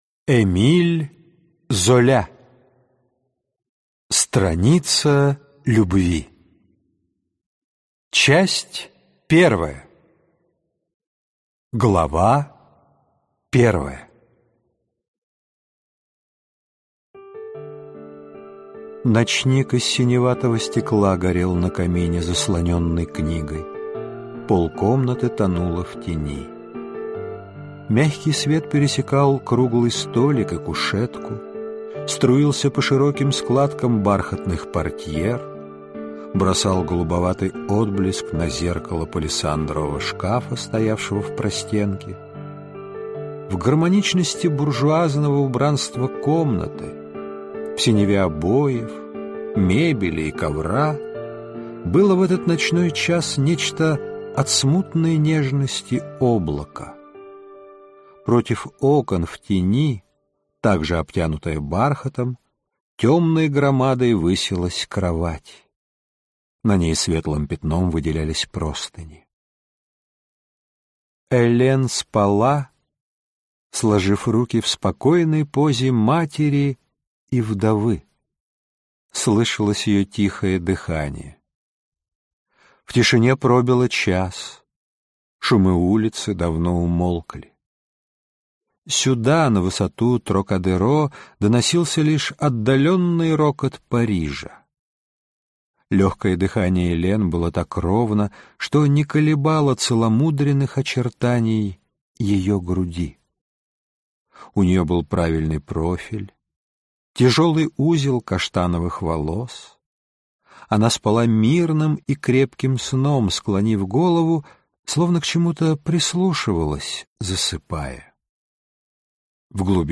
Аудиокнига Страница любви | Библиотека аудиокниг